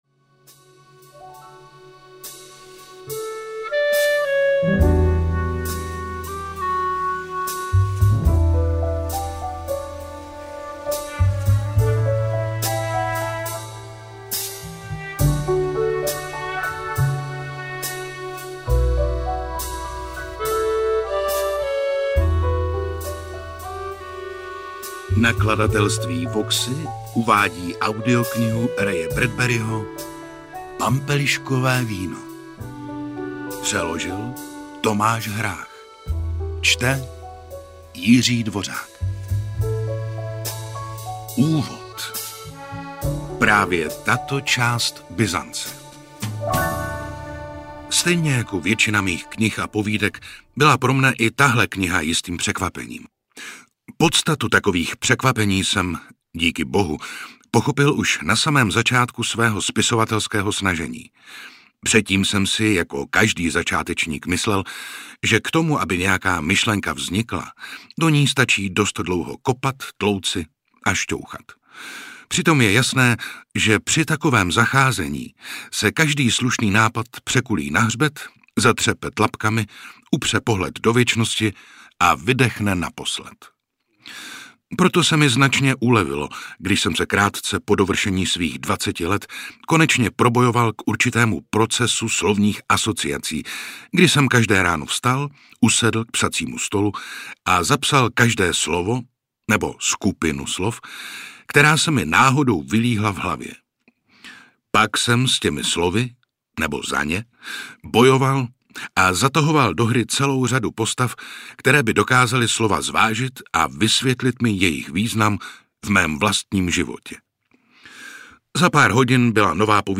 Pampeliškové víno audiokniha
Ukázka z knihy
• InterpretJiří Dvořák